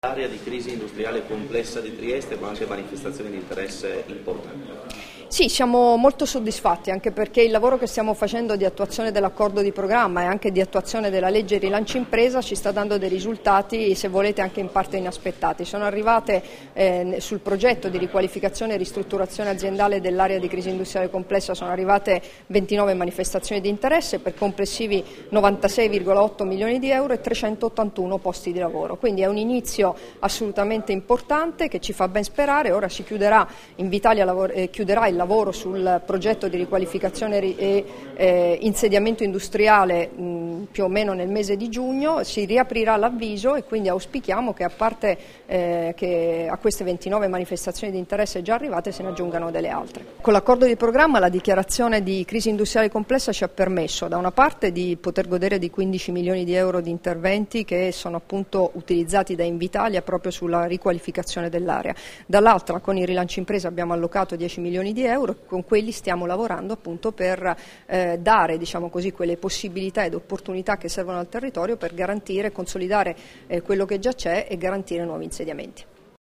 Dichiarazioni di Debora Serracchiani (Formato MP3) [1220KB]
a margine dell'incontro "Il rilancio strategico dell'area di crisi industriale complessa di Trieste. Il percorso e i vantaggi per le imprese e l'occupazione", rilasciate a Trieste il 20 maggio 2016